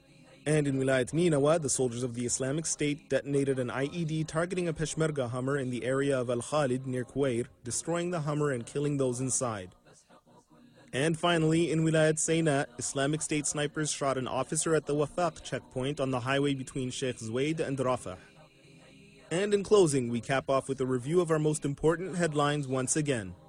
clip from an April broadcast (English-language) on Al-Bayan